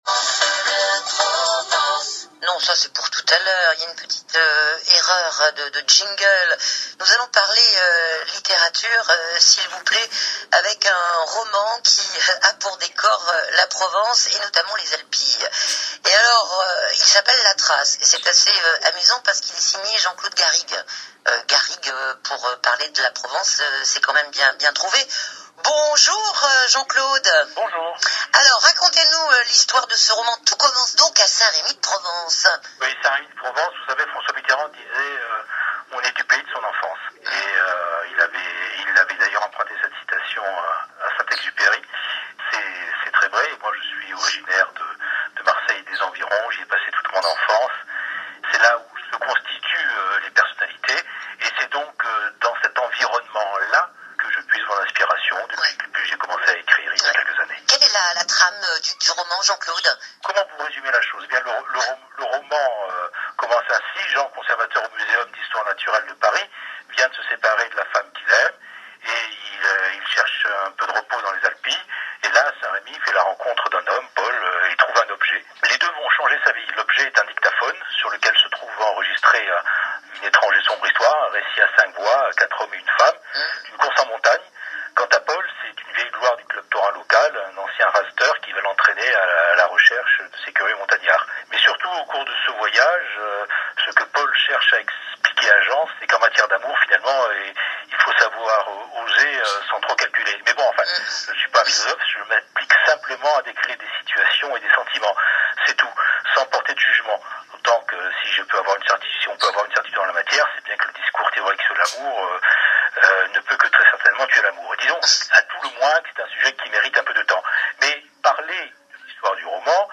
Émission Radio
Interview sur France bleu Provence